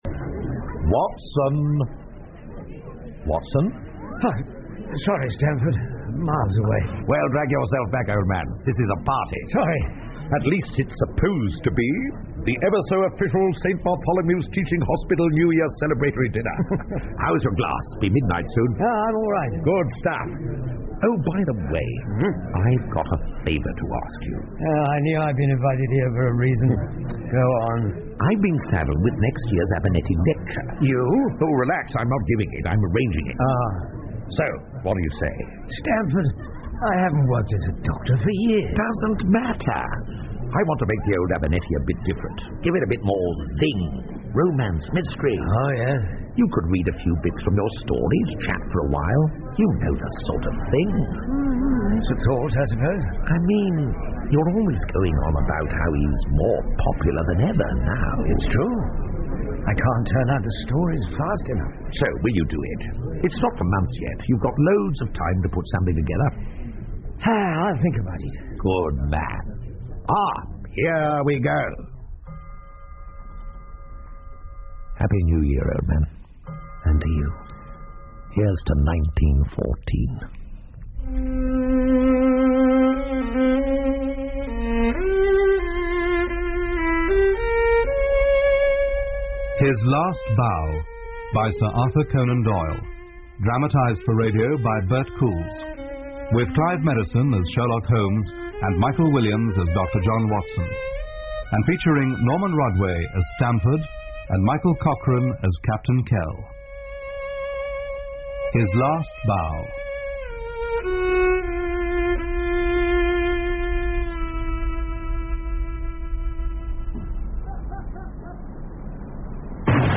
福尔摩斯广播剧 His Last Bow 1 听力文件下载—在线英语听力室